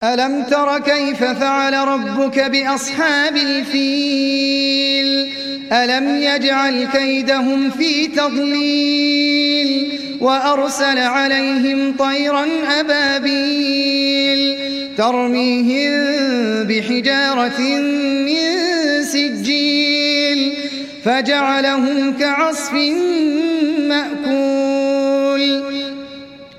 القران الكريم بصوت القارى احمد العجمي كامل